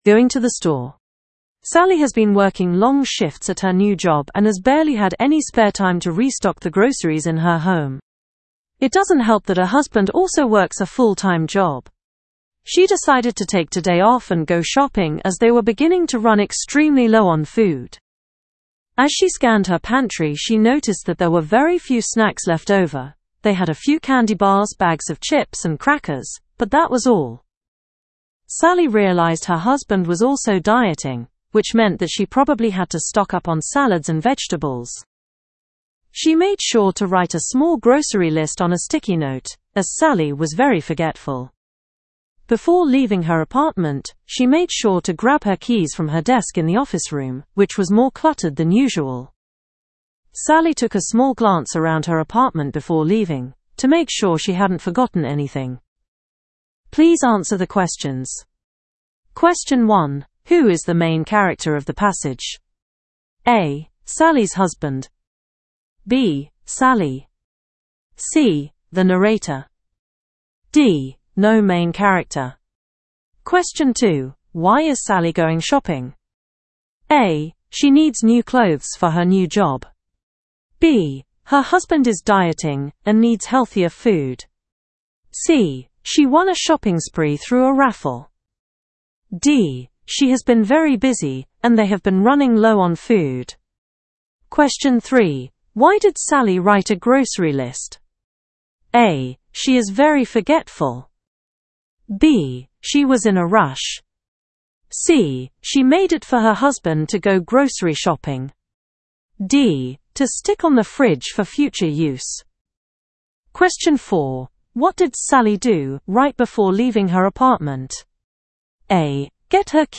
Inglaterra